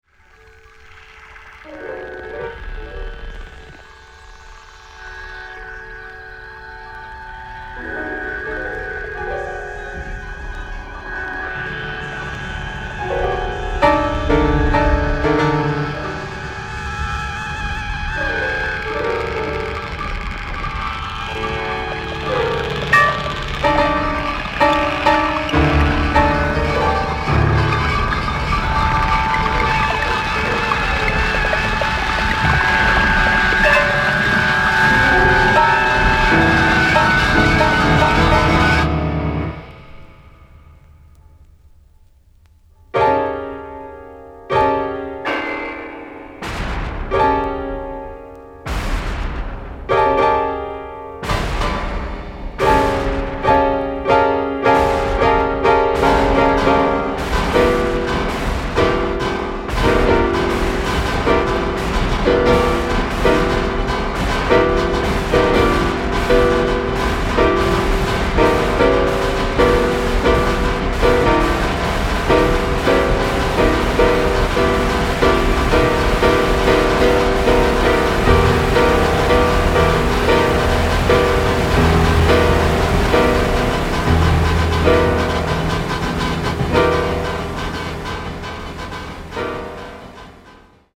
電子メディアと器楽を融合した二編のエレクトロアコースティック楽章を収録
サイキックなゆらぎを放つ響きの帯に